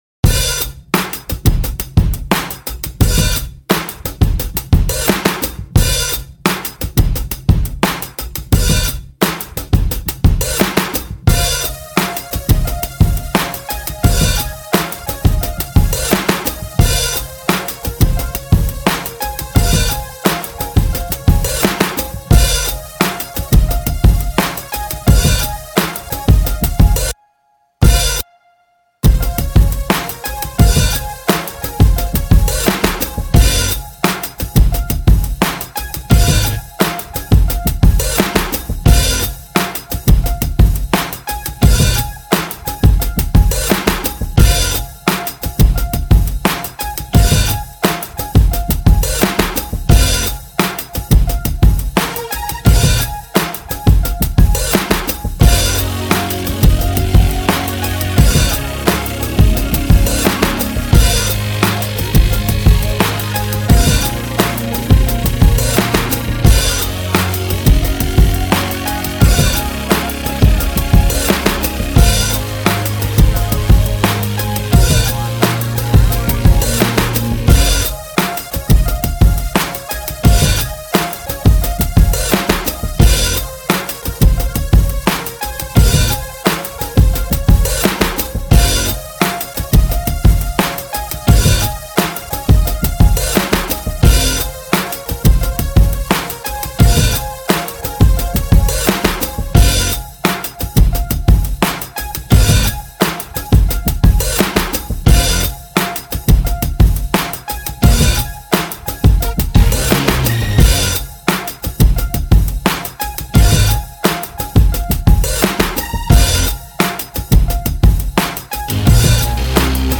23:16 Минусовка